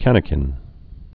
(kănĭ-kĭn)